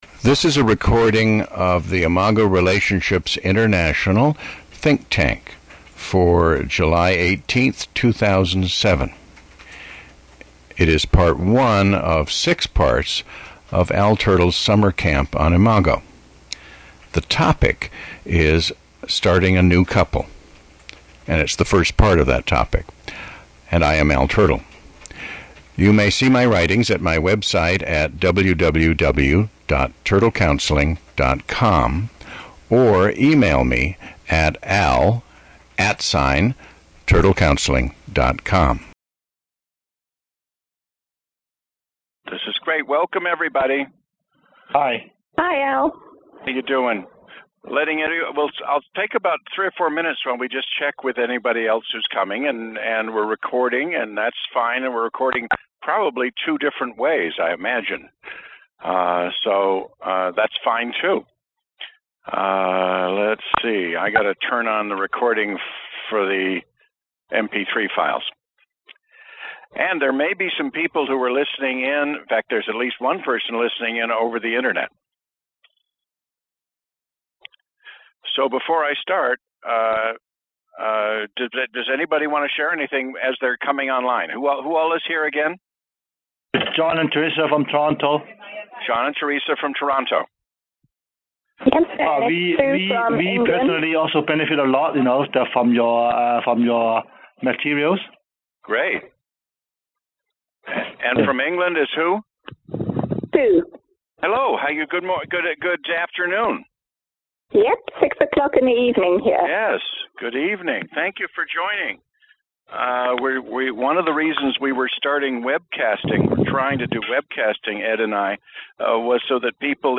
This class was taught by phone to a group of Imago Therapists in July of 2007.